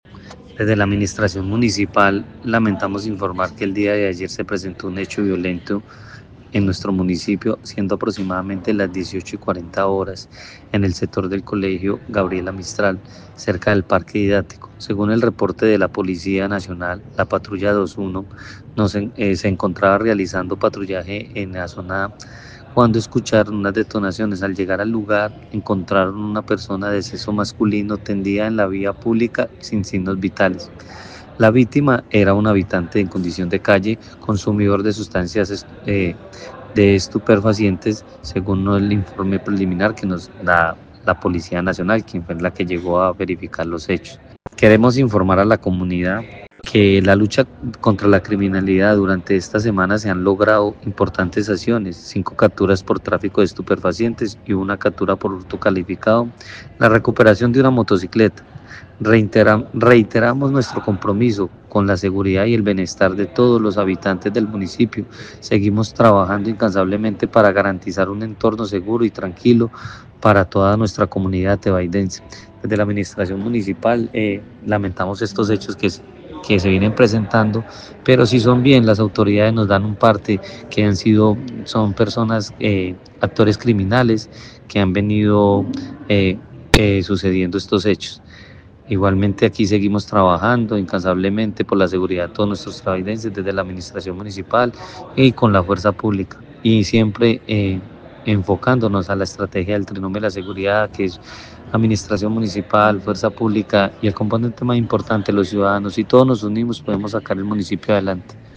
Secretario de Gobierno de La Tebaida